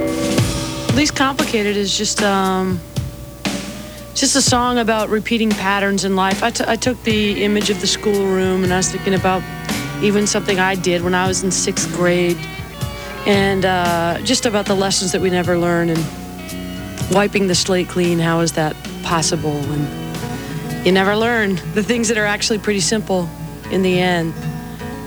04. interview (0:25)